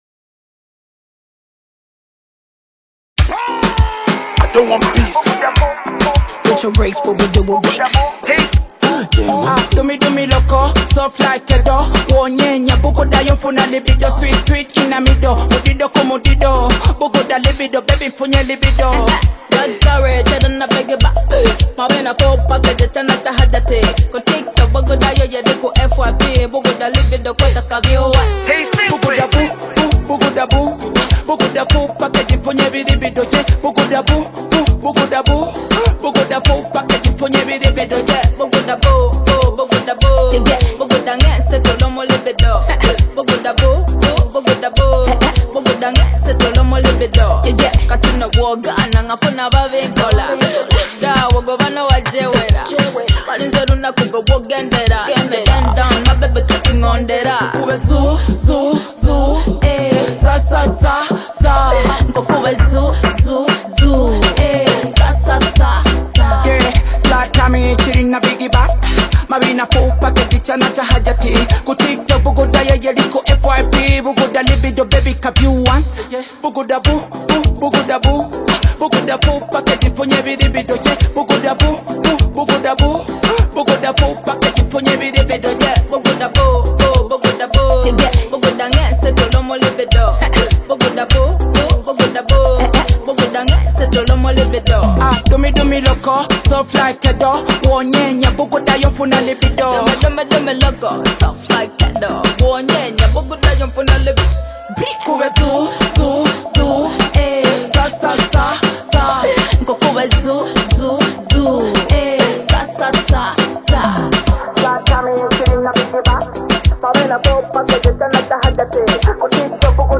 Ugandan rap
a song packed with energy, rhythm, and raw power.
More than just a dance track